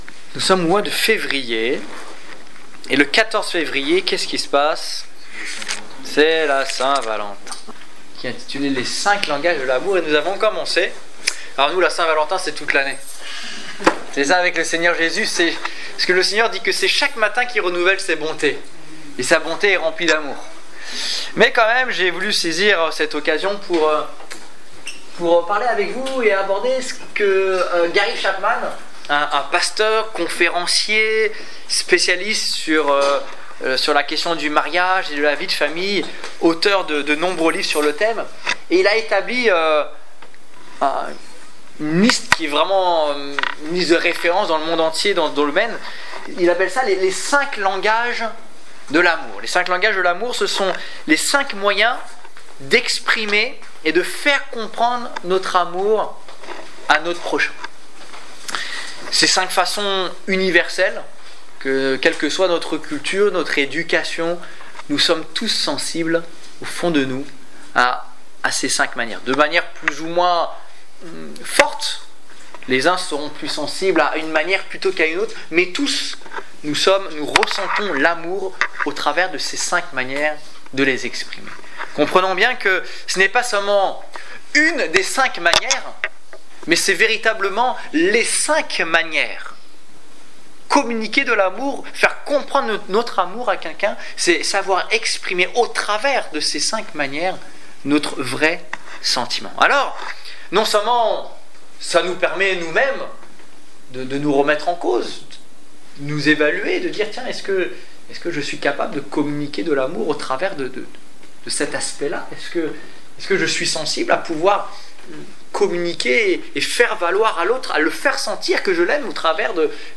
Évangélisation du 6 février 2015